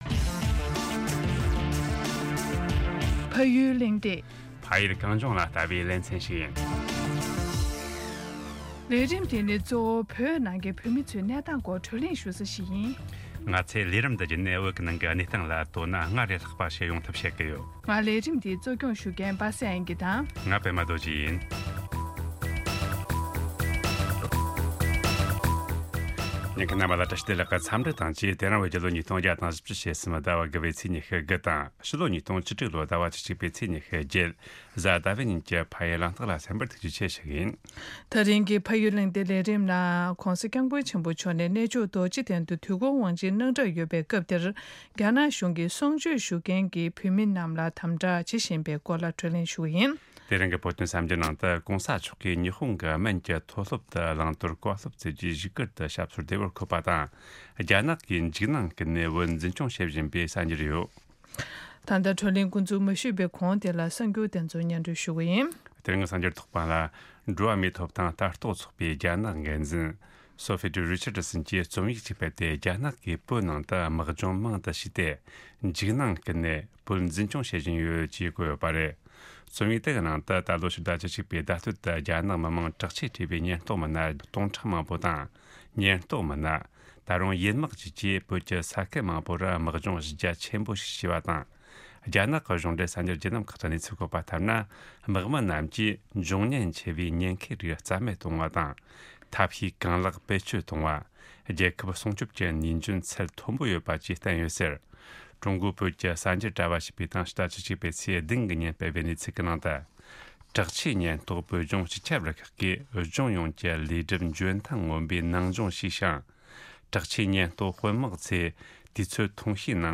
གདན་འདྲེན་ཞུས་ནས་བགྲོ་གླེང་ཞུས་པ་ཞིག་ཡིན།